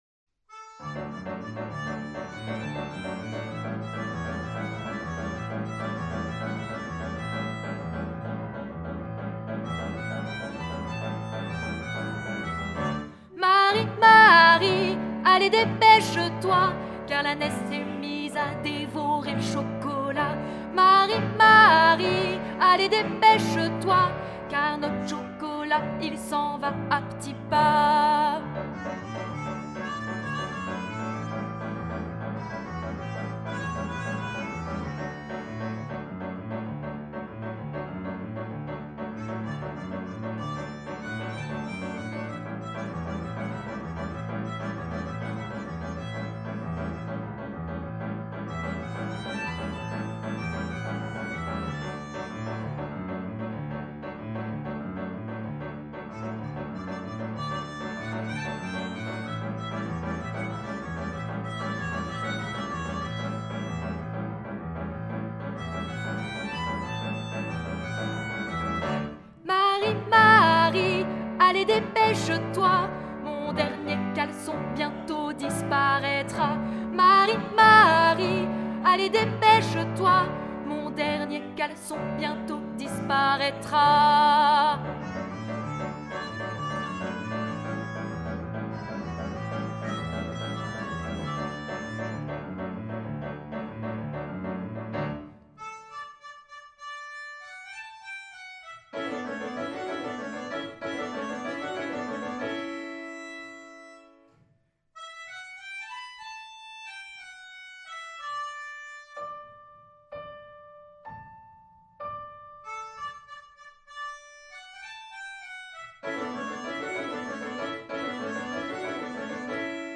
Genre :  ChansonComptine
Style :  Avec accompagnement
Une chanson à l'atmosphère chaleureuse et narrative, parfaite pour la période hivernale !
Enregistrement alto
Hacia Belelen - Alto.mp3